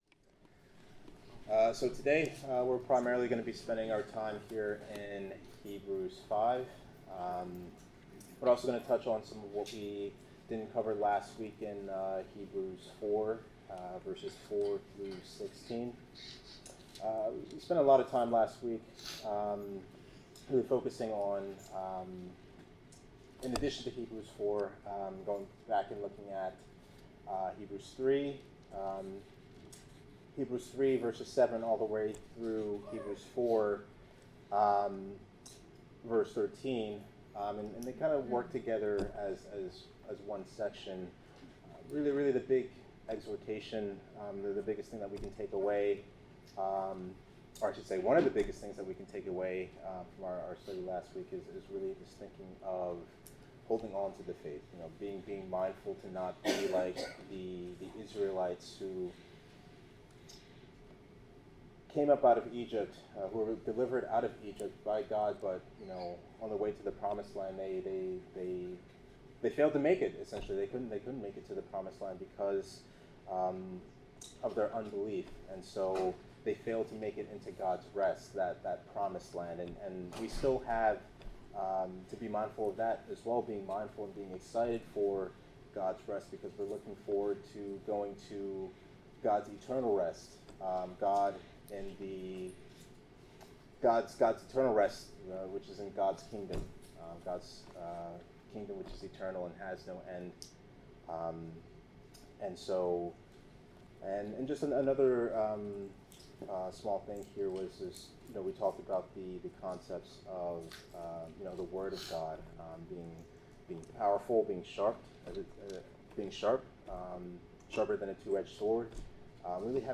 Bible class: Hebrews 5
Service Type: Bible Class